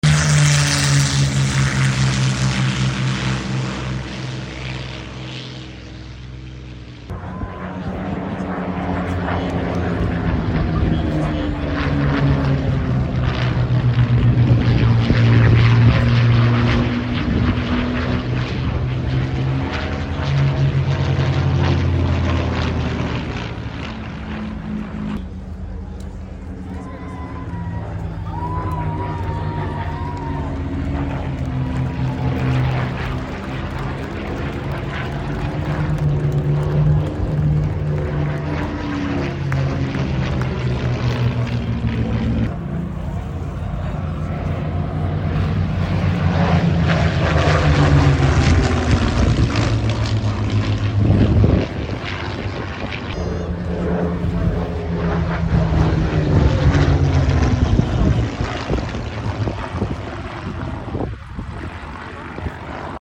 Duo spitfire display at Headcorn